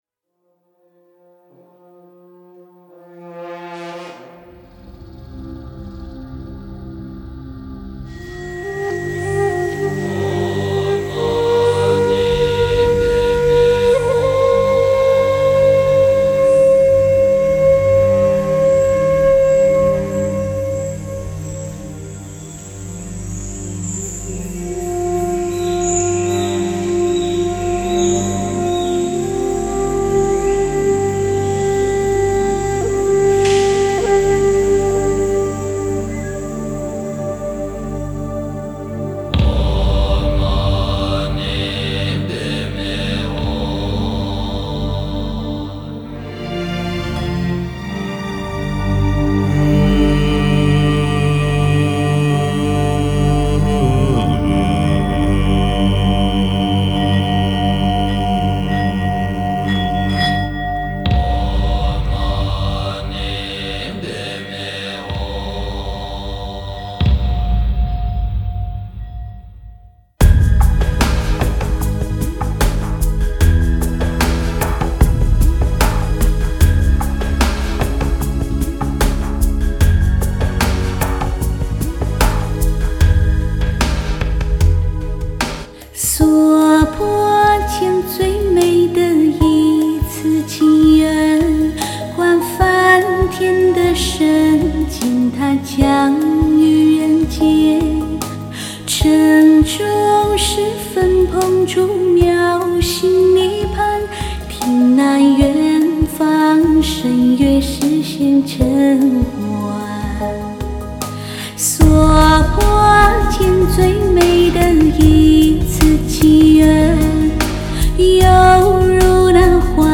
前段佛法，后段人声，没赶上分享